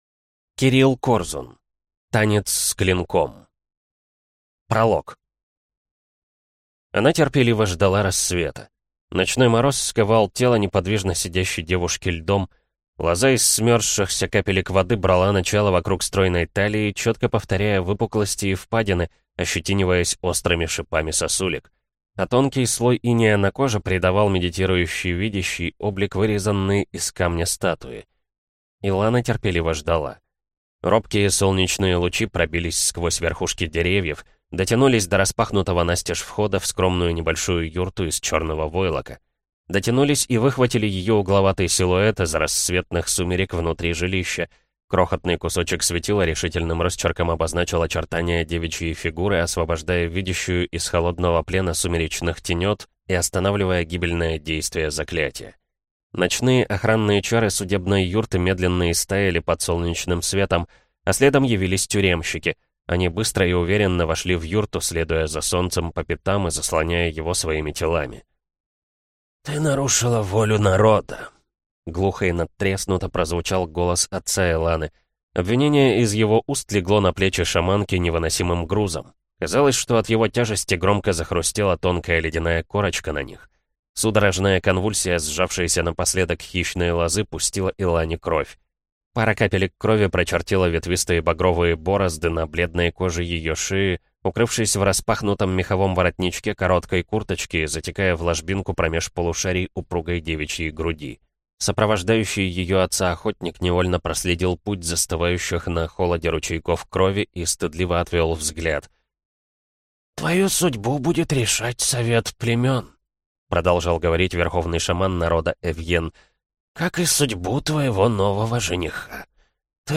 Аудиокнига Танец с Клинком | Библиотека аудиокниг